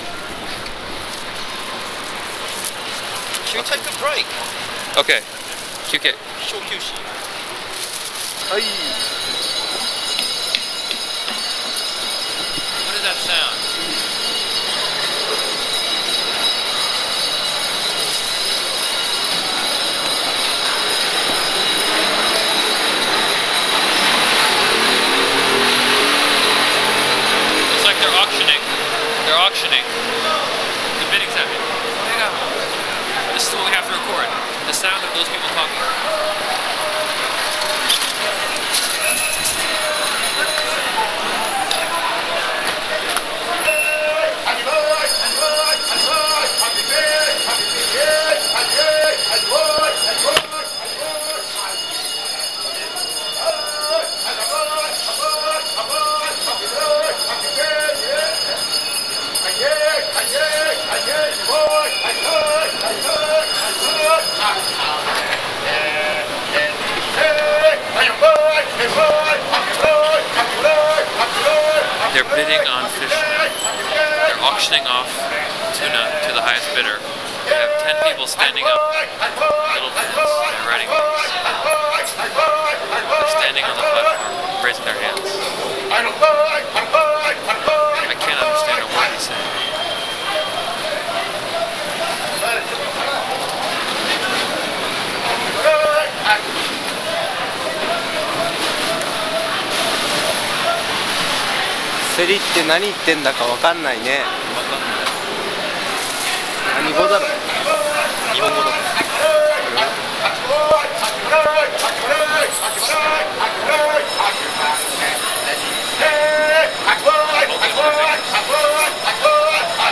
A bell summons the buyers as the bidding begins.